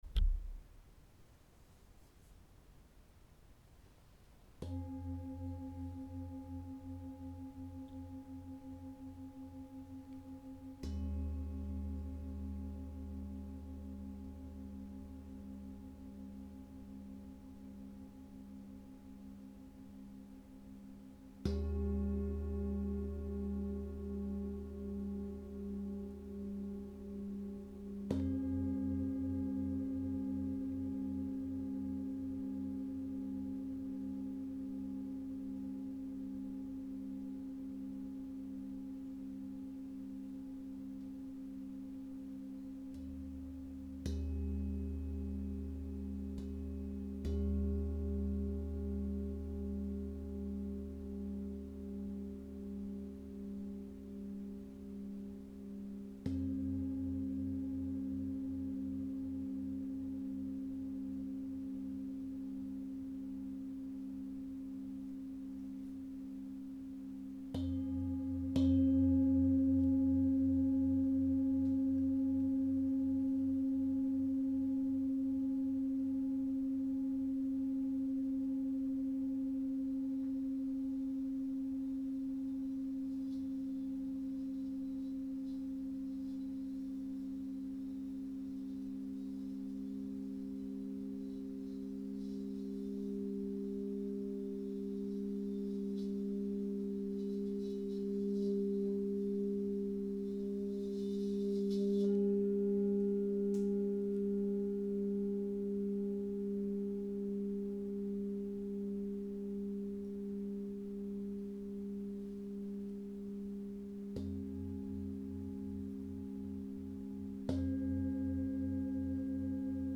Massage sonore et méditation guidée par les sons
Massage sonore aux bols tibétains, de cristal, gong et diapasons | Méditation sonore. Relaxation profonde, libération des tensions et blocages.